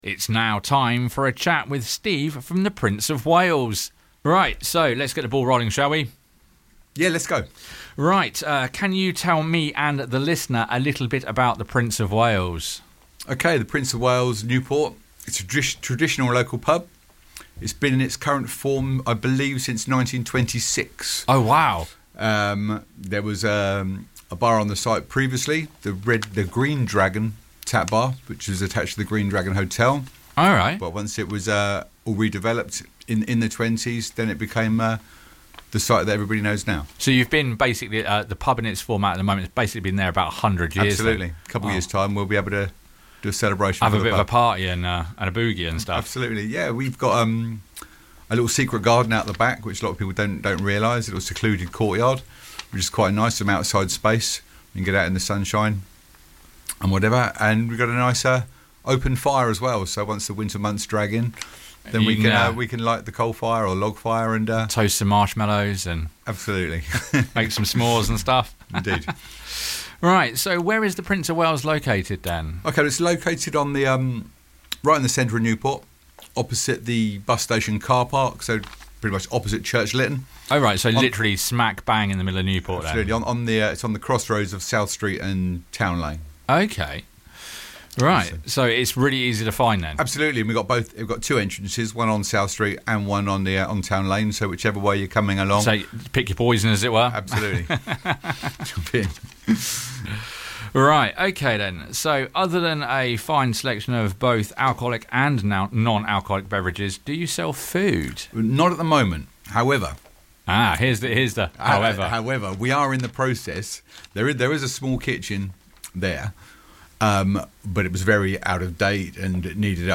came into the Vectis Radio studio for a chat
If you missed the original broadcast here is the podcasted version of the chat